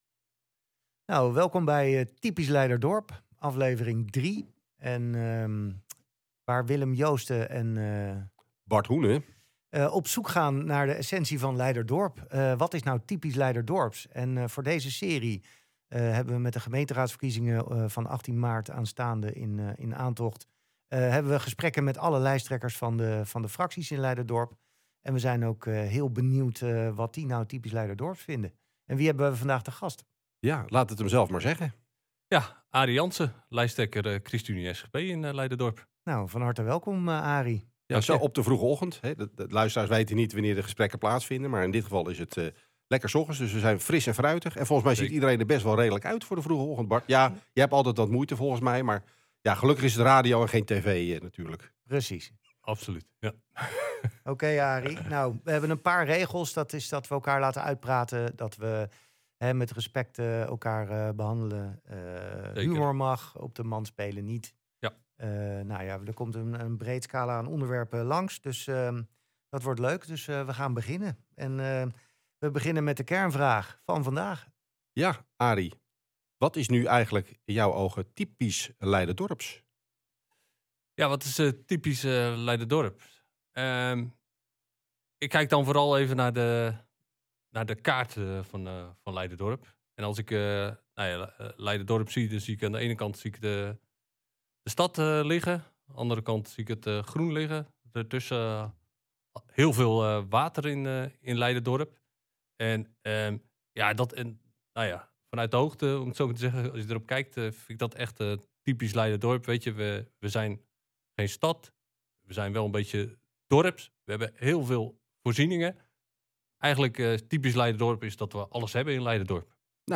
Typisch Leiderdorp is een podcast van twee opgewekte, ondernemende Leiderdorpers met hart voor het dorp.
eerlijk, scherp en vaak met een knipoog over wat er speelt in Leiderdorp.